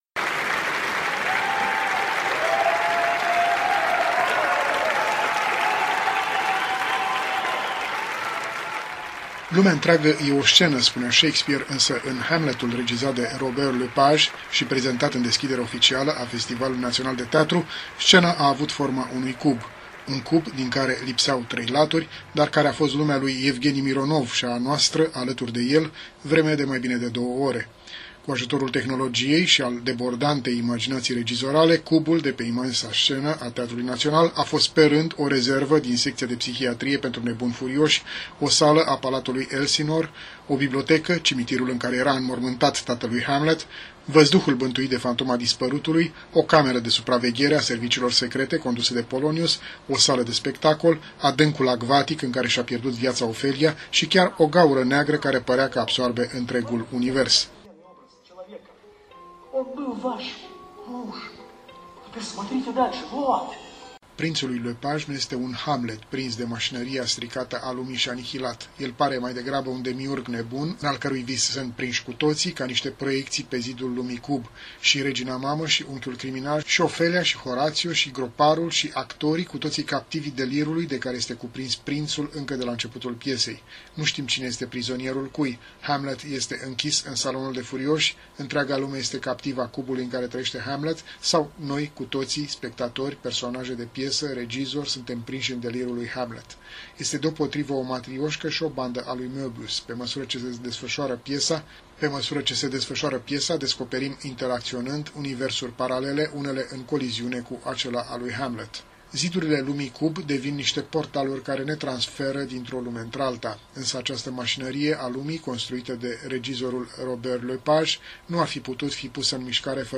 Europa liberă: Ne spuneți o impresie la cald despre acest spectacol, mai ales că l-ați cunoscut pe Robert Lepage, pe Mironov nici nu mai vorbesc?